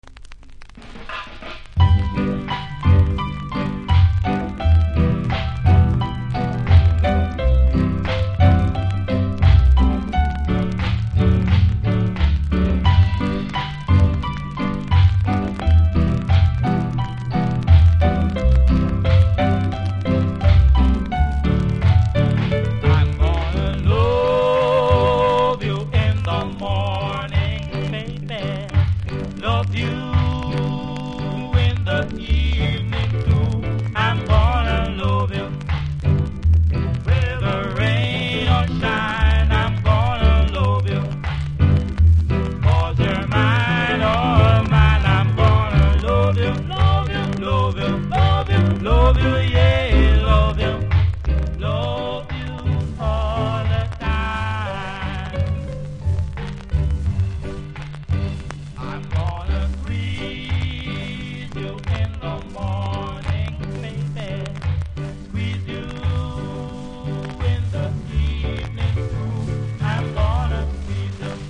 両面ラブソングのロック・ステディ♪
キズ多めノイズ多めでプレイ不可（音が下がる所まではプレイ可）
マスター起因で中盤から音が下がりますのでどっちみちプレイ不可。
素晴らしいロック・ステディなのに残念！